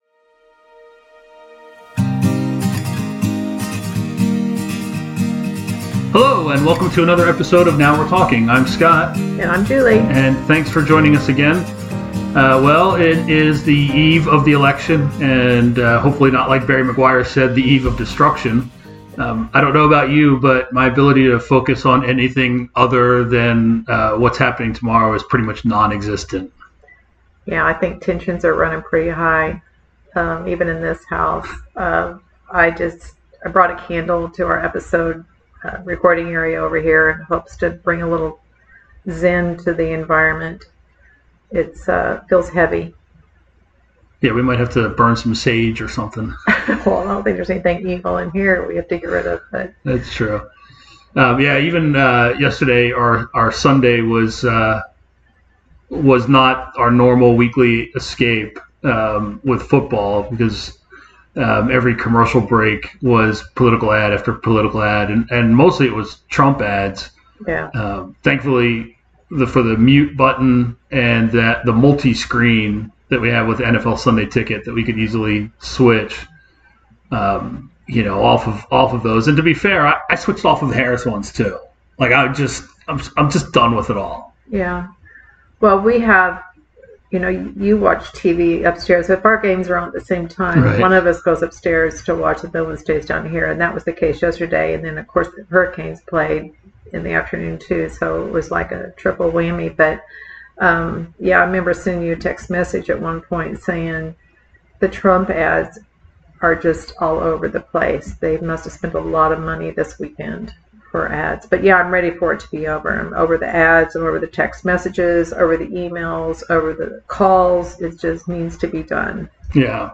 Just your average couple with pretty significant differences in ages, life experiences and upbringings. We like to talk and discuss a wide variety of topics around the proverbial dinner table and we thought we'd bring a wider audience into our conversations.